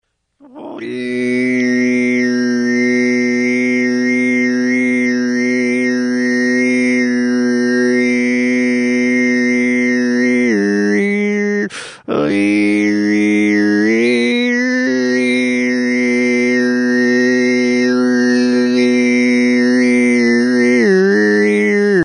A clean source of 3 note throat singing...
high4.mp3